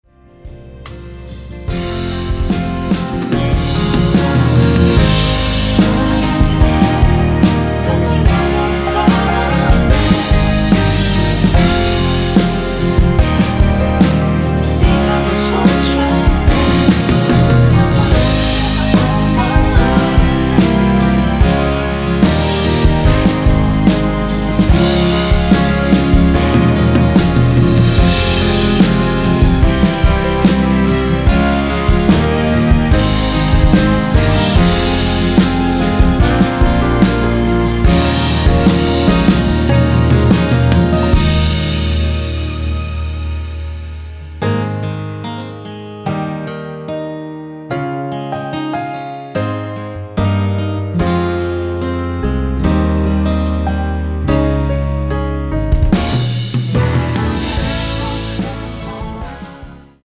원키에서(-2)내린 코러스 포함된 MR입니다.
앞부분30초, 뒷부분30초씩 편집해서 올려 드리고 있습니다.